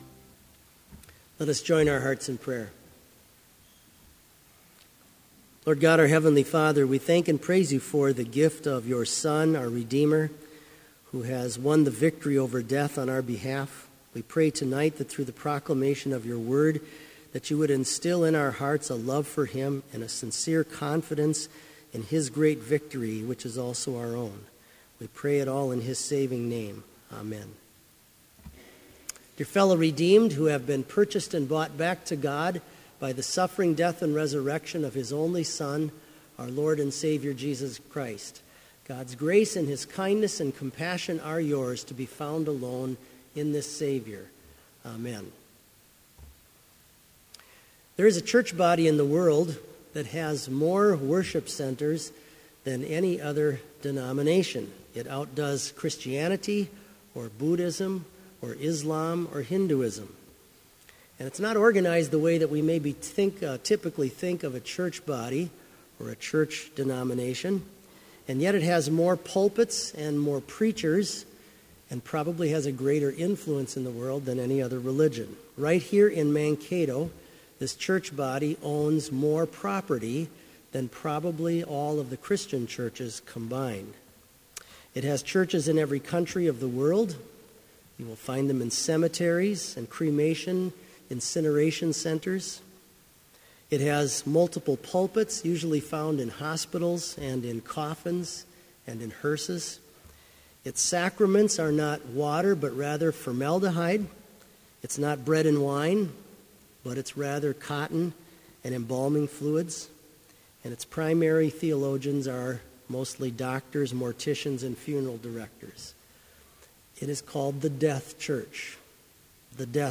• Prelude
• Versicles & Gloria Patri (led by the choir)
• Homily
• Benedicamus & Benediction (led by the choir)